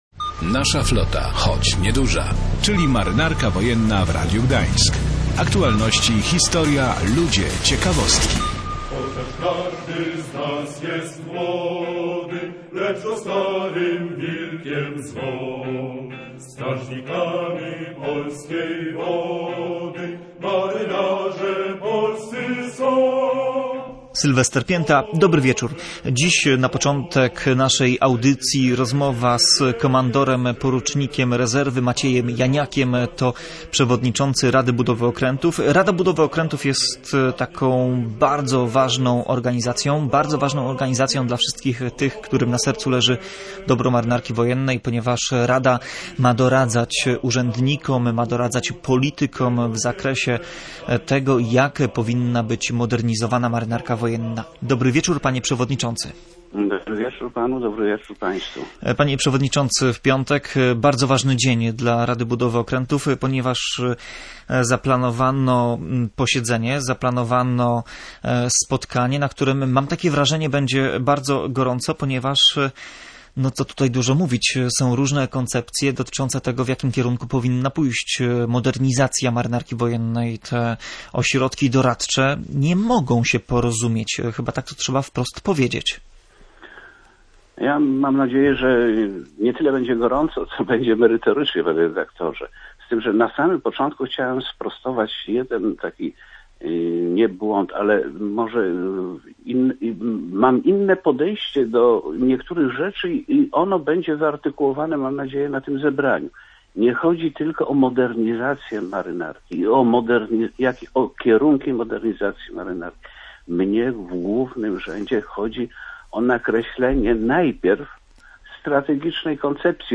Dziś w audycji „Nasza flota choć nieduża” rozmowa na temat bardzo ważnego – dla środowiska Marynarki Wojennej – spotkania, które odbędzie się w piątek na Akademii Marynarki Wojennej w Gdyni. Zaplanowano na ten dzień posiedzenie Rady Budowy Okrętów, która jest ciałem doradczym dla urzędników i polityków.